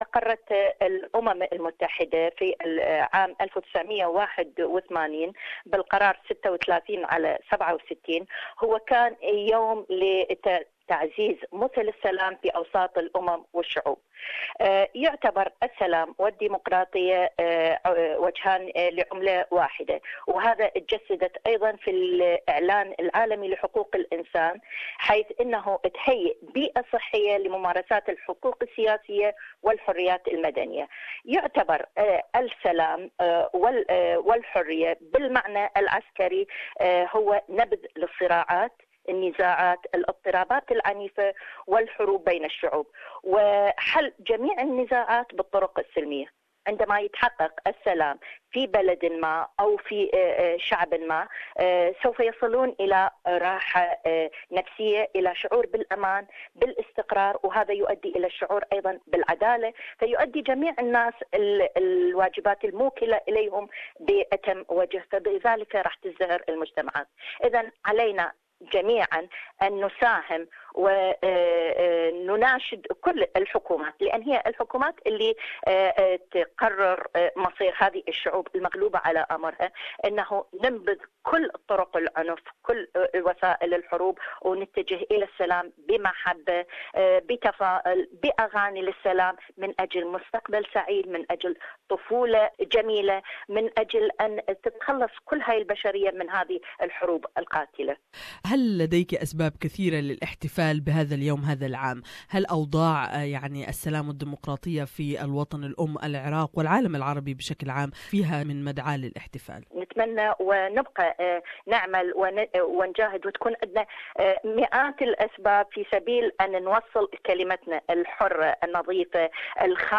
Each year the International Day of Peace is observed around the world on 21 September. The General Assembly has declared this as a day devoted to strengthening the ideals of peace, both within and among all nations and peoples. More in this interview